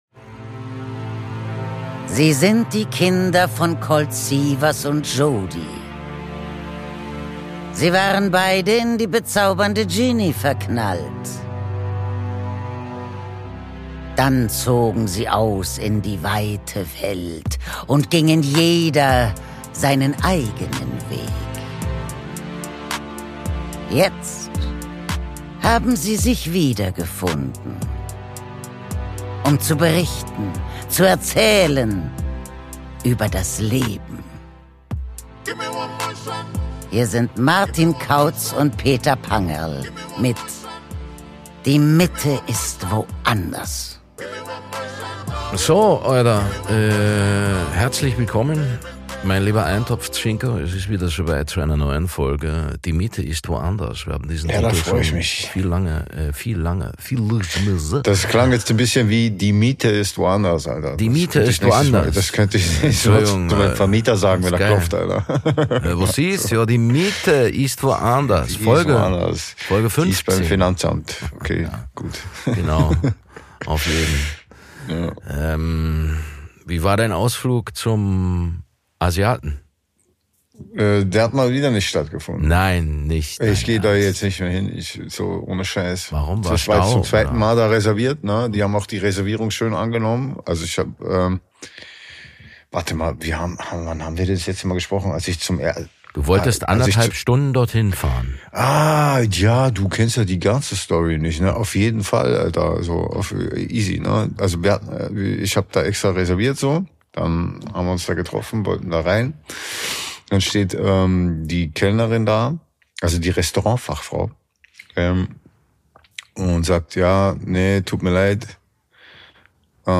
Aus dem 25h Hotel im Wiener Museumsquartier